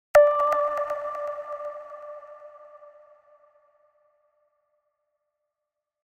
Scifi 4.mp3